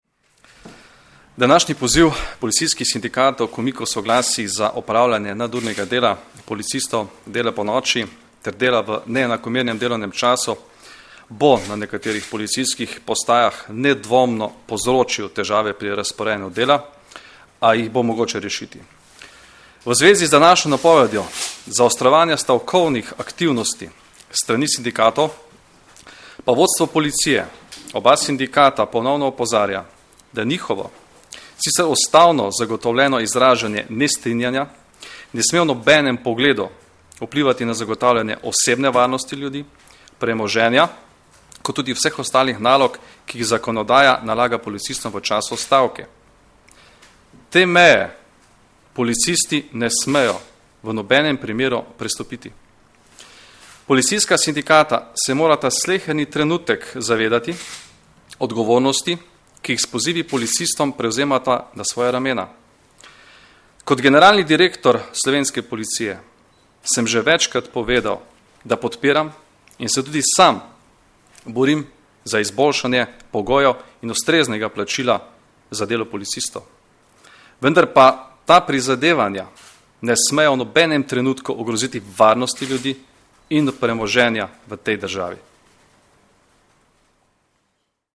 Zvočni posnetek izjave generalnega direktorja policije Janka Gorška (mp3)